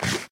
eat2.ogg